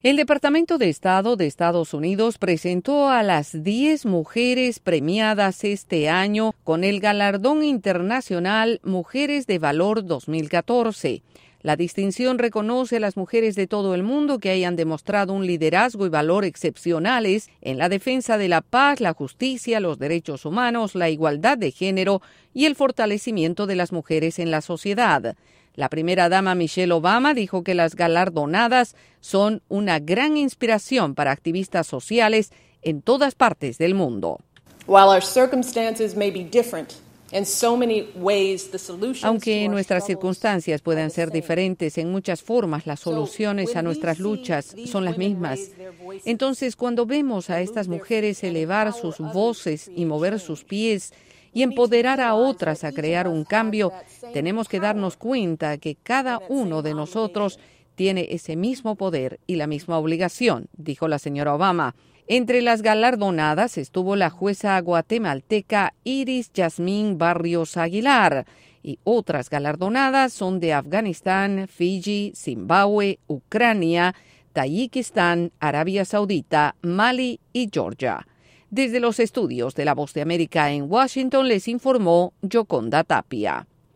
El Departamento de Estado de Estados Unidos entregó el premio Mujeres de Valor 2014. Desde la Voz de América en Washington DC informa